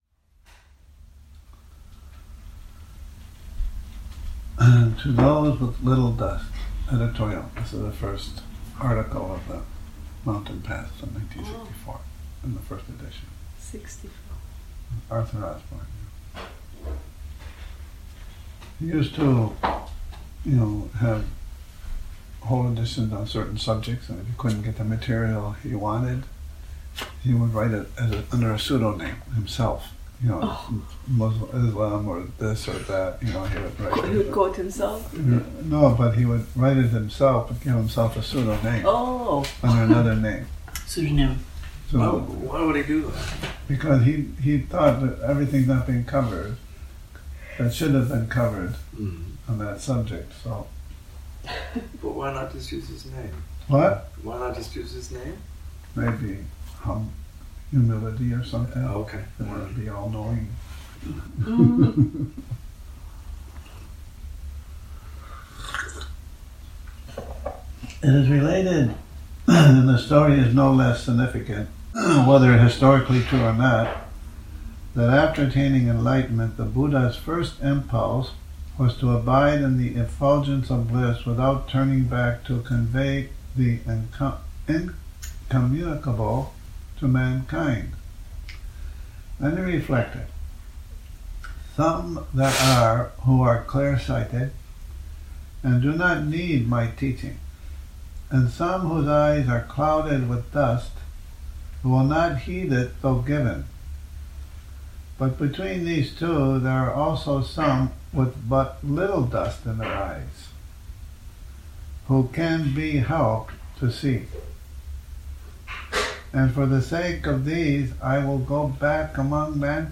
Morning Reading, 04 Oct 2019
an 04 Oct 2019 reading from 'For Those with Little Dust' Morning Reading, 04 Oct 2019 this recording has alot of distortion